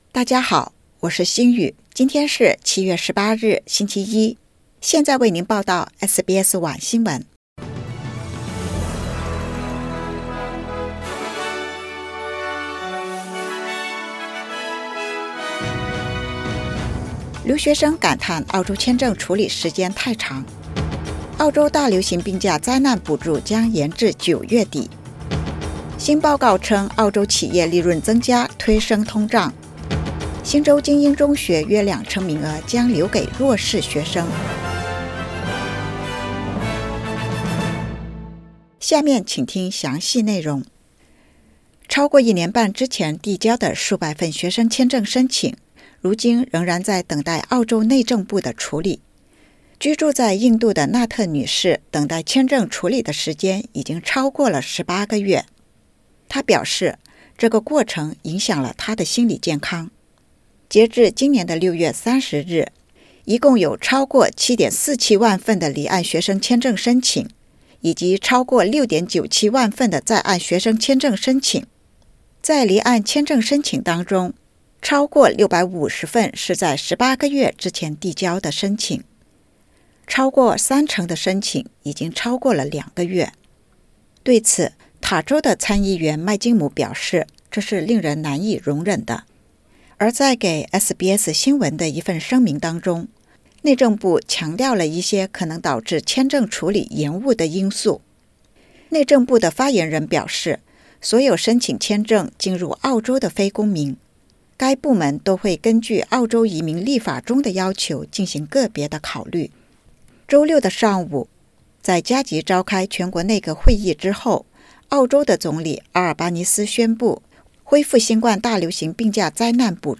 SBS晚新闻（2022年7月18日）